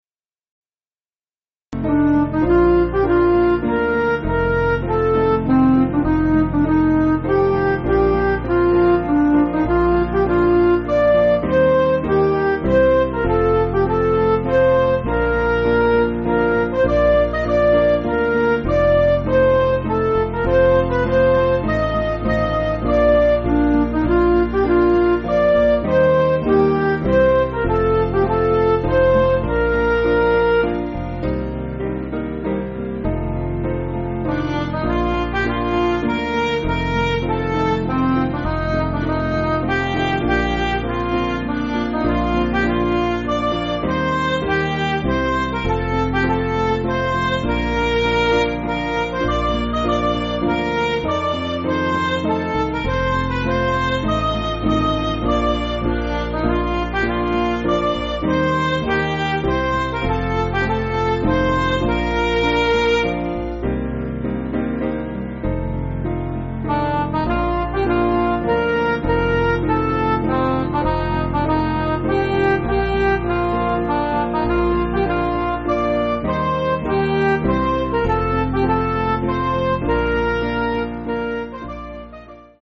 8.7.8.7.D
Piano & Instrumental
(CM)   4/Bb